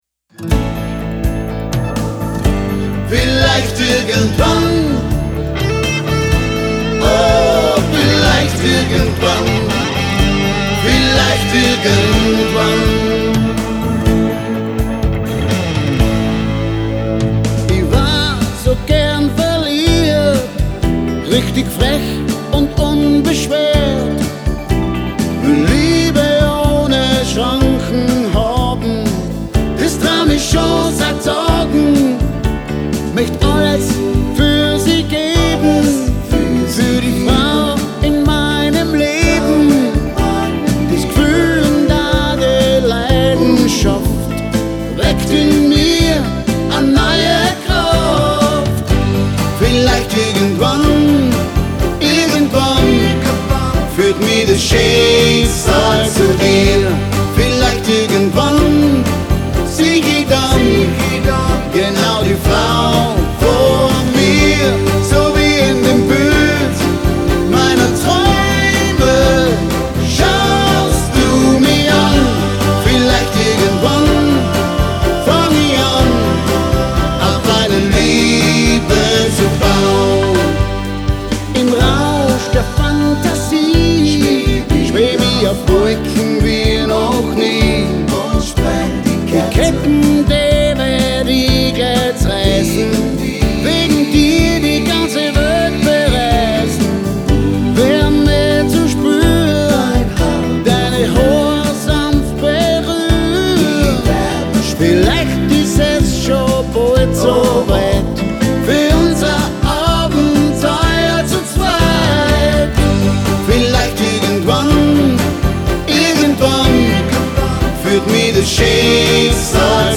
Partyband, Liveband
Tanzmusik
• Coverband